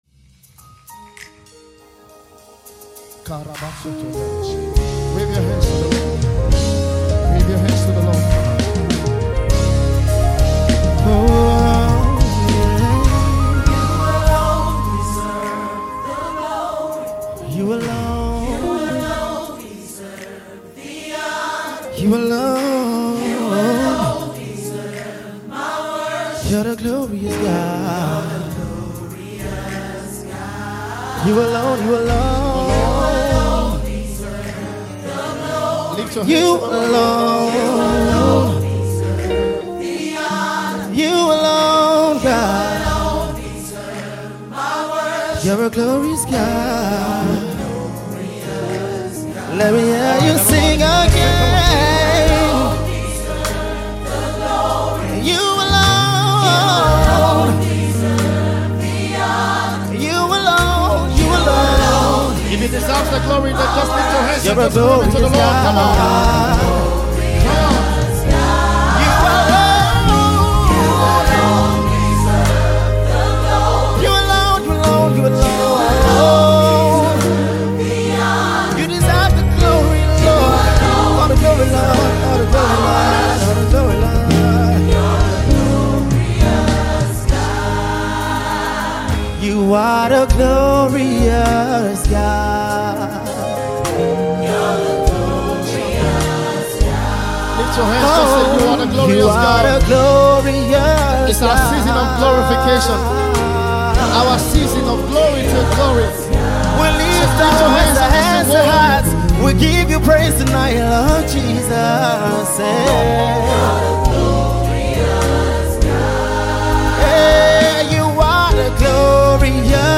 gospel music group